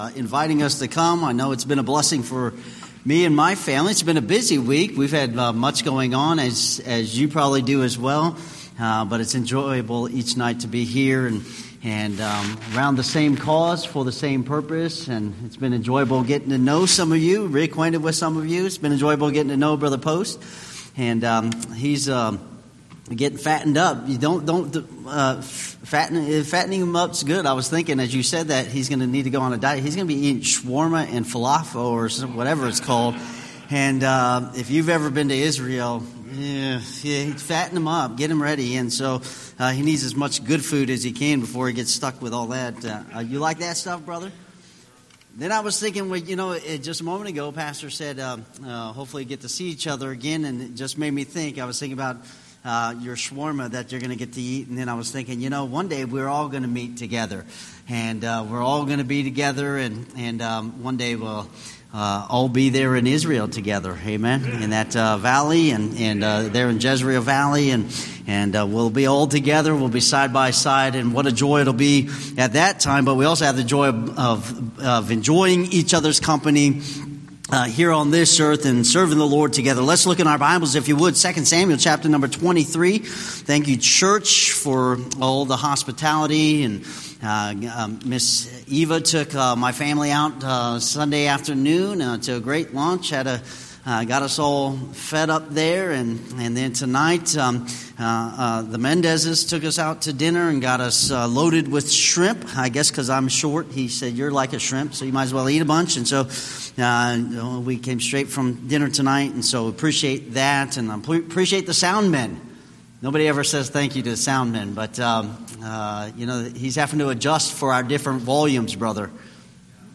Series: Missions Conference